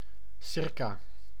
Ääntäminen
Synonyymit handeln für Ääntäminen : IPA: /ʊm/ Haettu sana löytyi näillä lähdekielillä: saksa Käännös Ääninäyte 1. circa 2. eromheen 3. rondom 4. met 5. om 6. rond 7. op 8. per Esimerkit Es geht um den Kuchen.